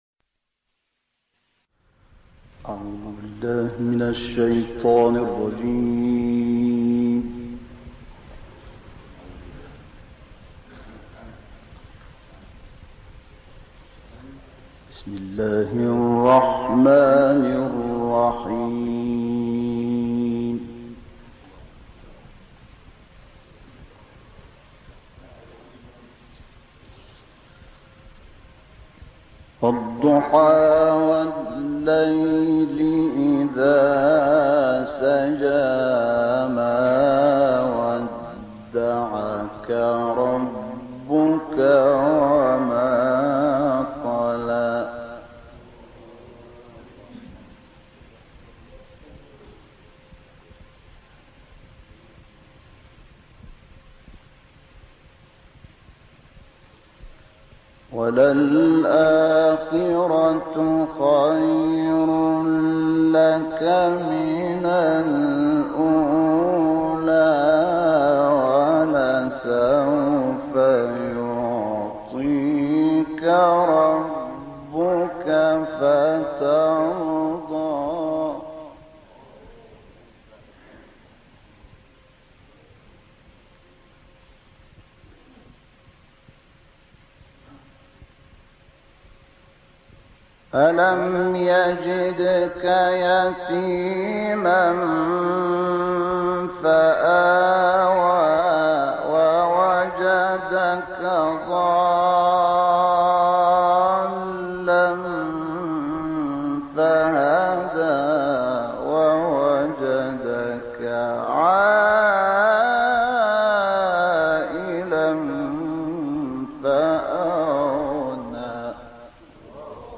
سوره انشراح با تلاوت استاد شحات محمد انور+ دانلود/ سه موهبت بزرگ در انشراح
گروه فعالیت‌های قرآنی: شاهکاری از تلاوت مرحوم شحات محمد انور از سوره‌های ضحی، انشراح، تین، علق، قدر، بیّنه، زلزله و عادیات ارائه می‌شود.